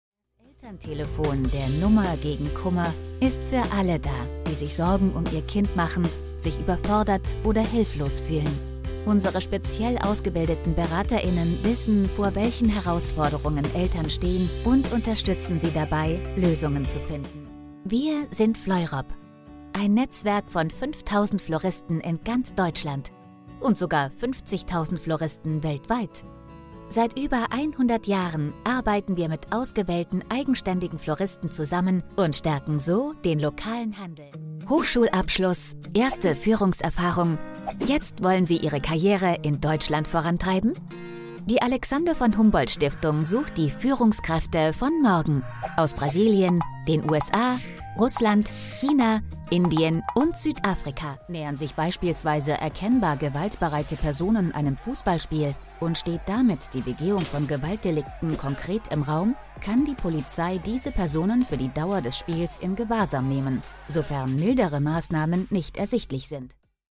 Female
Approachable, Assured, Authoritative, Bright, Character, Confident, Conversational, Cool, Corporate, Energetic, Engaging, Friendly, Natural, Posh, Reassuring, Smooth, Soft, Upbeat, Versatile, Warm
Microphone: Austrian Audio OC18